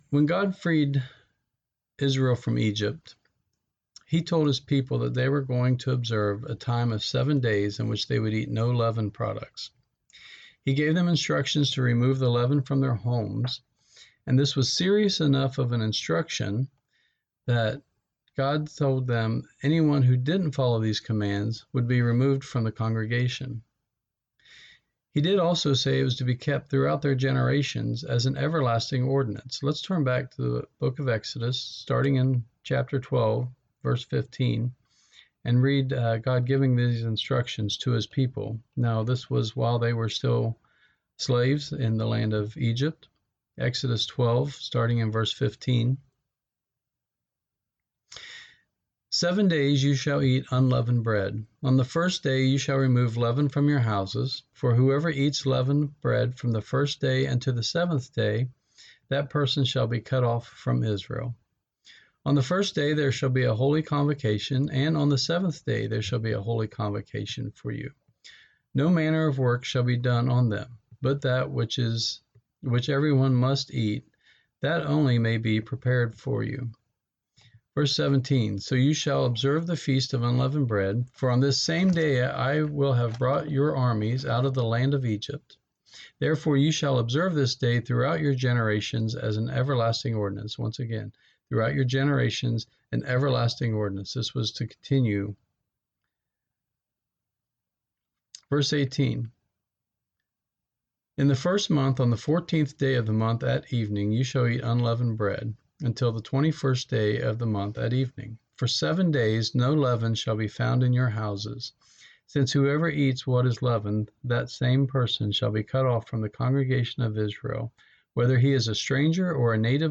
In this sermon we will examine the meaning of the Days of Unleavened Bread.